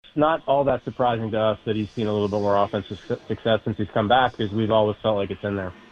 Jared Triolo is finally finding his groove at the plate since his return from Indianapolis, and the GM says the team has always thought there was more offense in Triolo’s game than he’s showed.